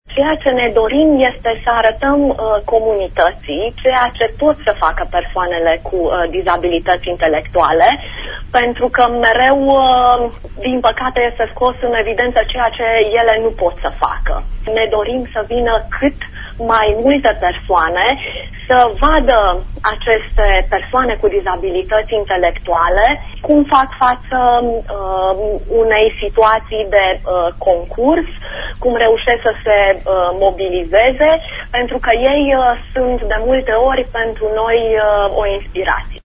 Invitată în emisiunea “Pulsul zilei” de azi